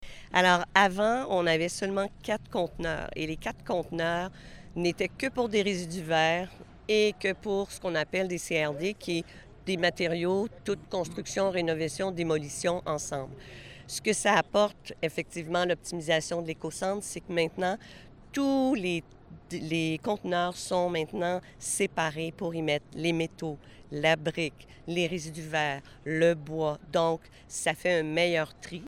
Lors d’une conférence de presse à Saint-Léonard-d’Aston jeudi, la RIGIDBNY a présenté ses nouvelles installations.